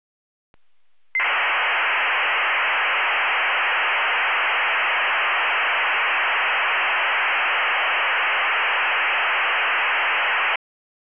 Digital scrembler, with DQPSK pi/4
Частота манипуляции (Baud Rate) 2400 Hz, в этой записи 2373
Частота несущей (Carrier frequency) 1800 Hz, в этой записи 1780
Модуляция, в которой сигнал принят (RX mode) NFM